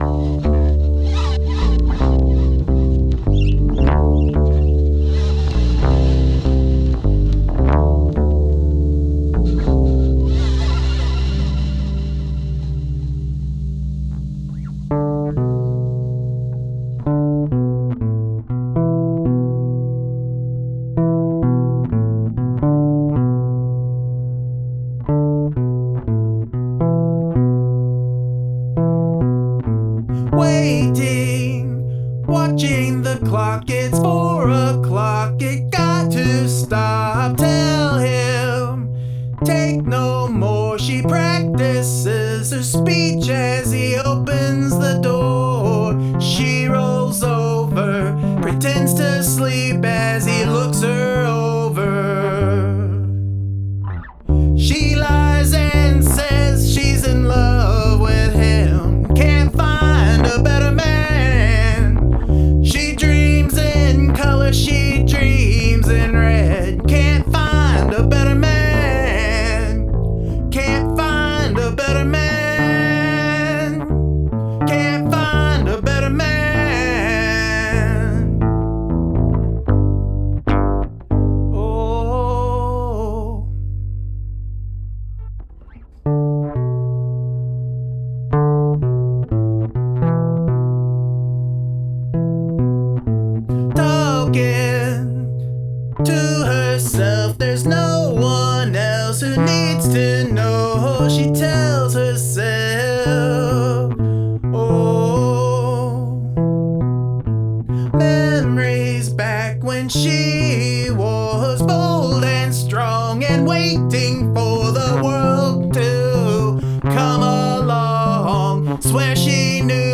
Brilliant sound from that bass,...
Great sounding bass.